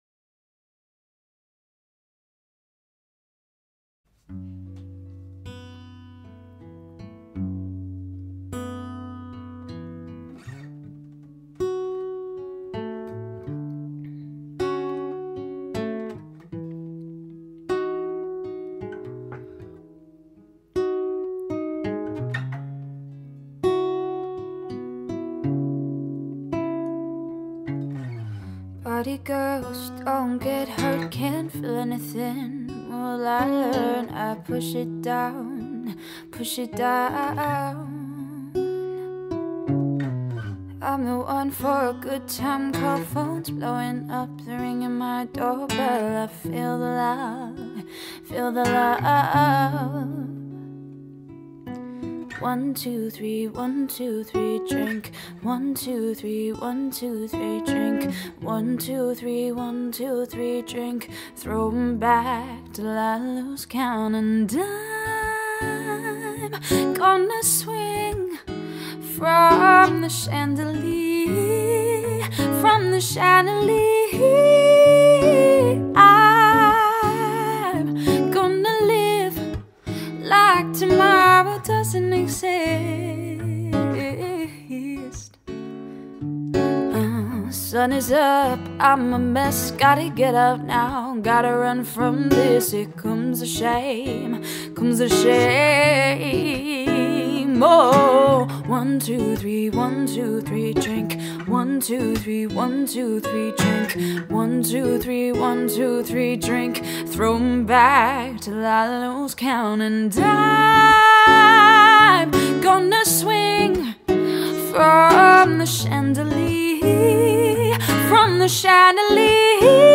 Vocals, Guitar, Looping, Beatboxing